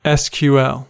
Uttal
Uttal US Ordet hittades på dessa språk: engelska Översättning 1.